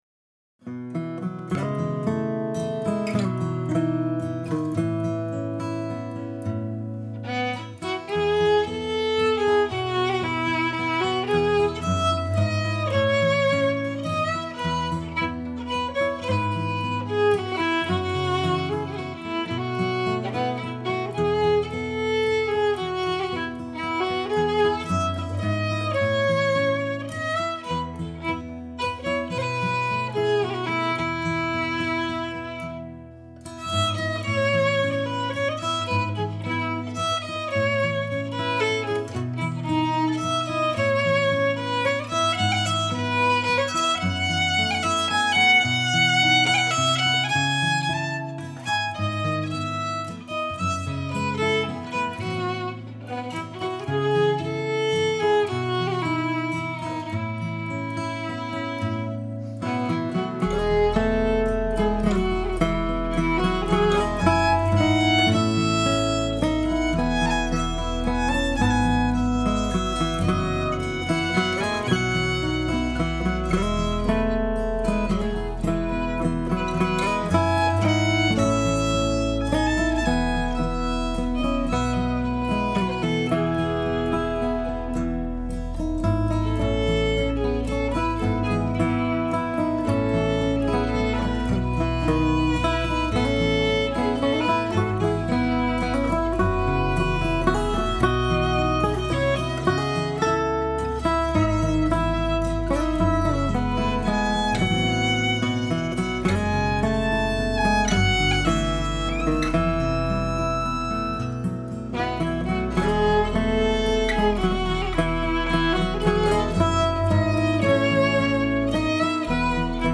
fiddle
guitar and mandolin
waltz